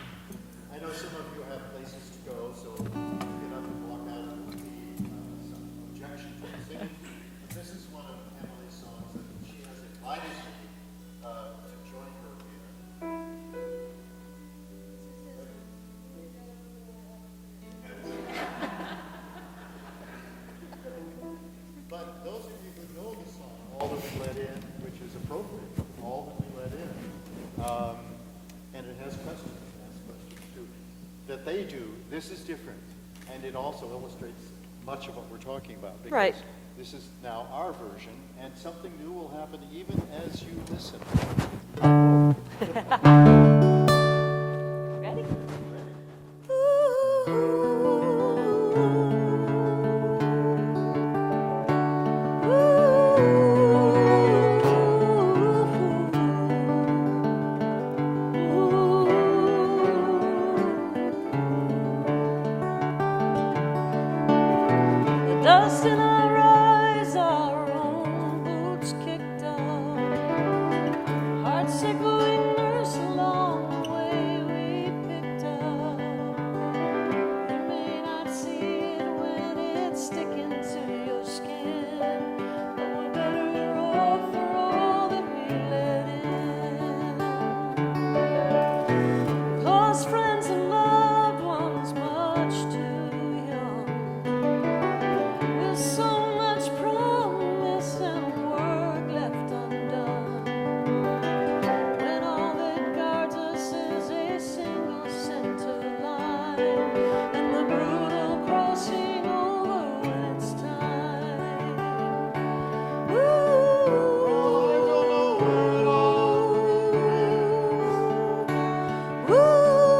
(audio capture from youtube)